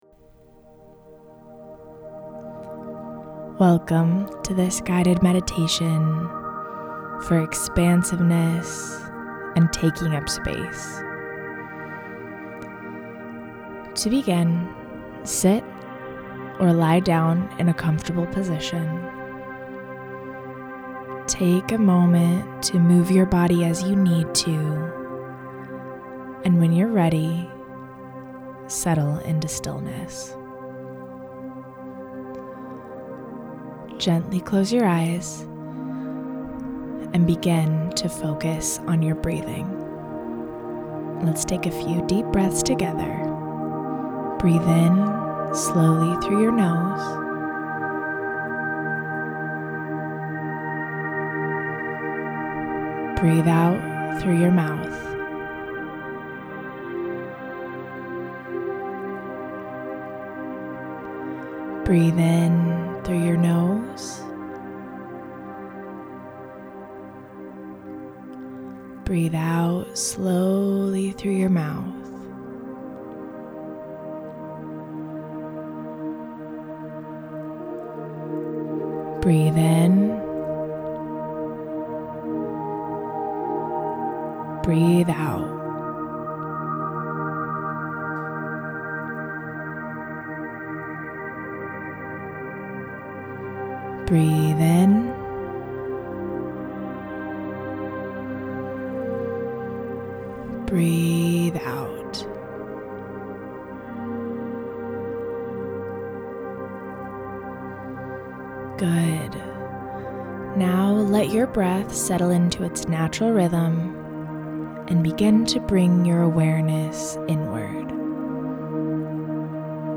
Guided Meditation – Barefoot Business